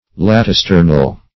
Search Result for " latisternal" : The Collaborative International Dictionary of English v.0.48: Latisternal \Lat`i*ster"nal\, a. [L. latus broad + E. sternal.]